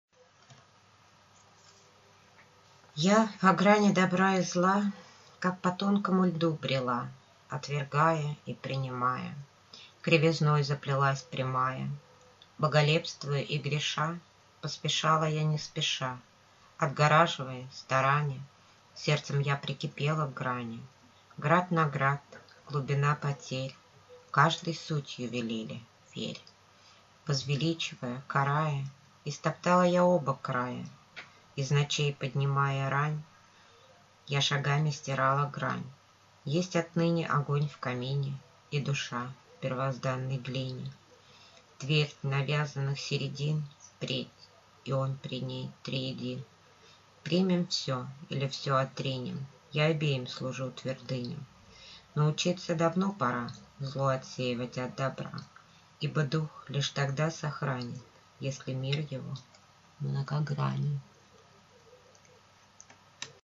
Слушать авторское прочтение: «Я по грани добра и зла»